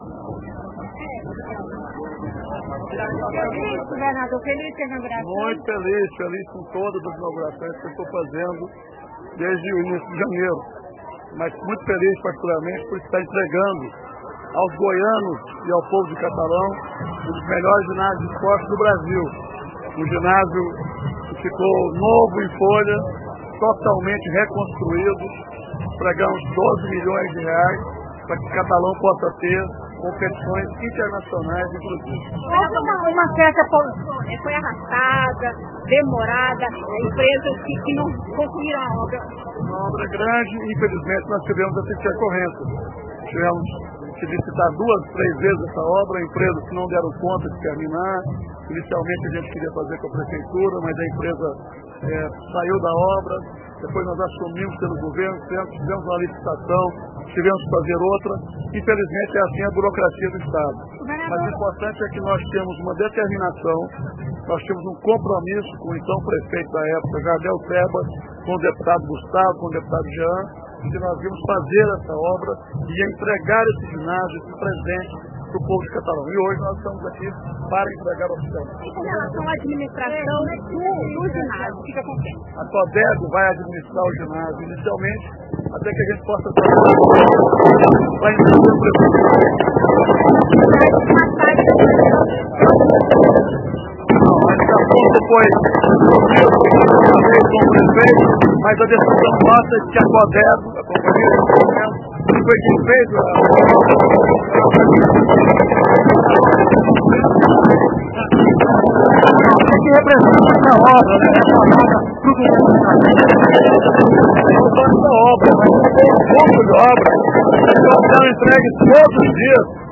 entrevista-Marconi0Aentrevista-Marconi.mp3